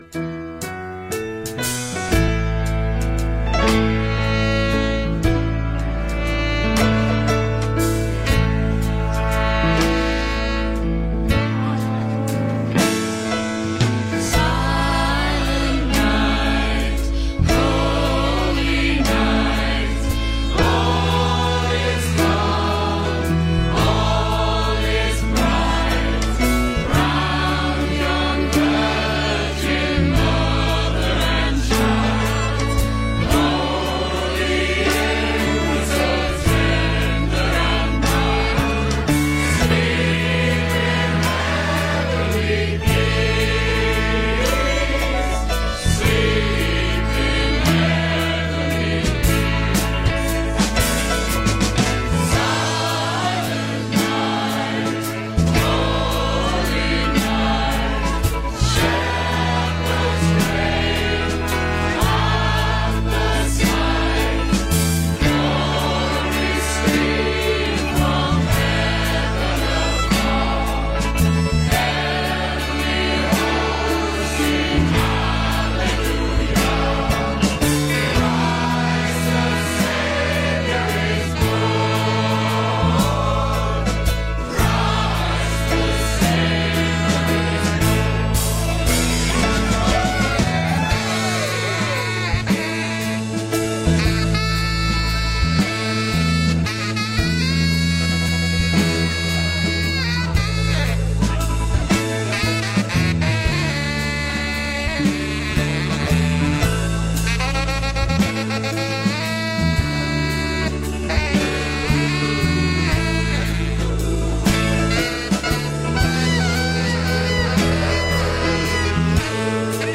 In session at The BBC